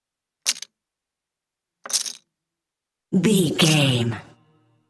Casino 5 chips table x3
Sound Effects
foley